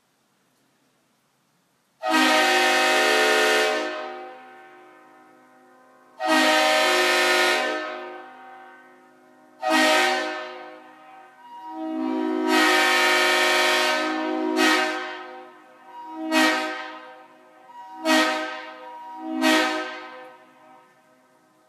Known as the "King of Horns" because of its deep, commanding, and haunting tone, this horn is getting rare on class ones today and getting replaced with Nathans. The 'RS' power chambers give this horn a distictive squeal when starting to blow.